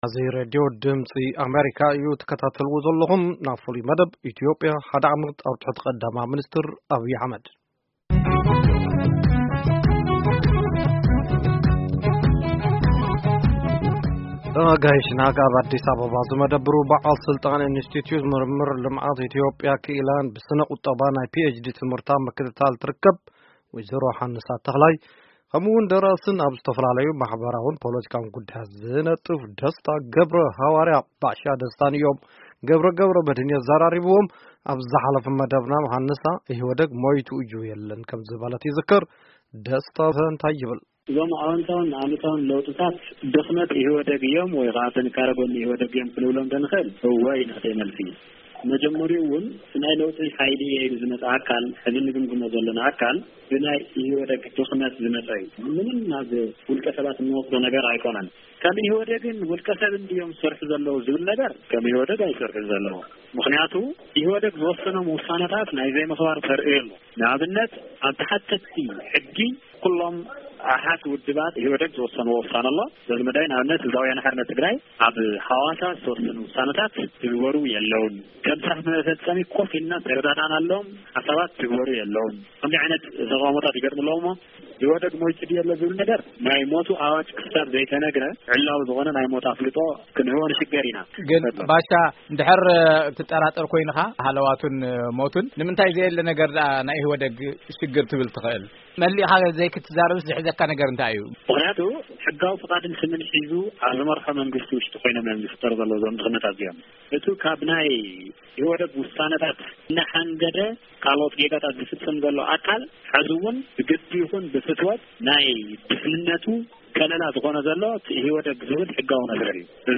ናይ ኢትዮጵያ ቀዳማይ ሚንስትር ኣብይ ኣሕመድ ስልጣን ካብ ዝሕዙ ሓደ ዓመት የቑጽሩ ኣብ ዘለው'ሉ ሕጂ እዋን ኣብዚ ዝሓለፈ ሓደ ዓመት ኣብ ትሕቲ መሪሕነቶም ዝተመዝገቡ ውፂኢታትን ጉድለታትን ብዝምልከት ንፖለቲካ እታ ሃገር ብቐረባ ዝከታተሉ ክልተ ኣጋይሽ ኣዘራሪብና ኣለና።